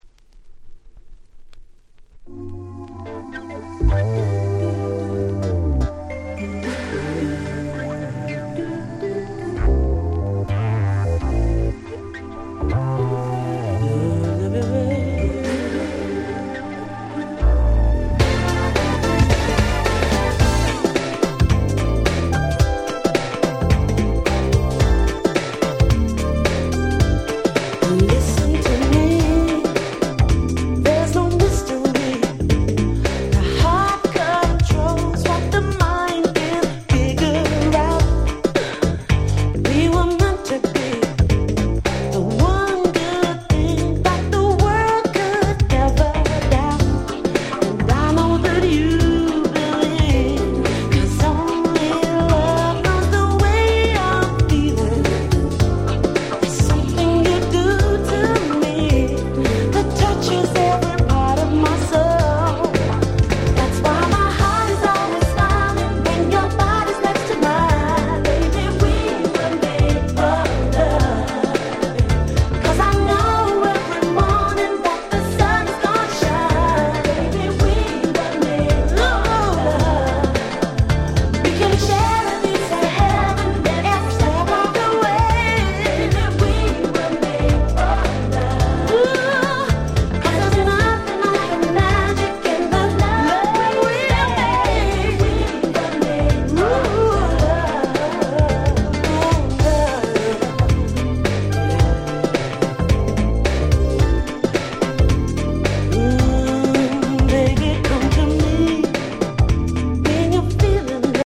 90's UK Soul UK R&B